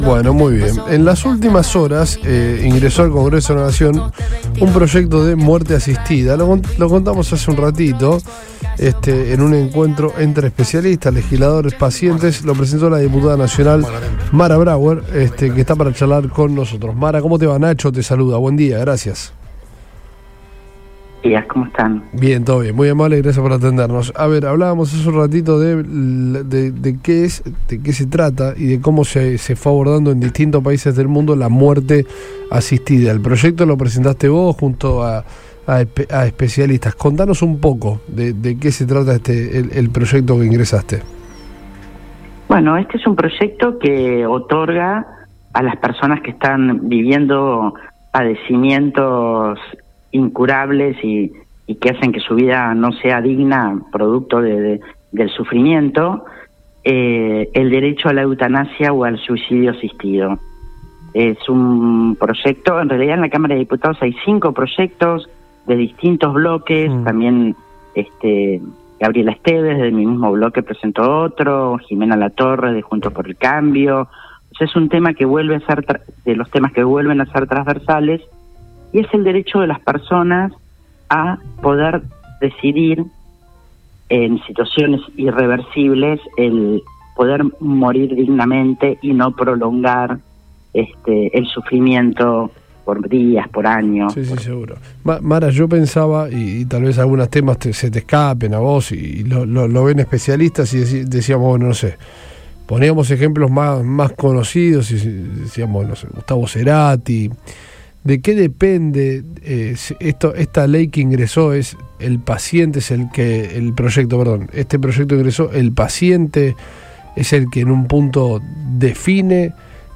La funcionaria dialogó con el equipo de Todo Pasa en Radio Boing, donde dio detalles de la idea.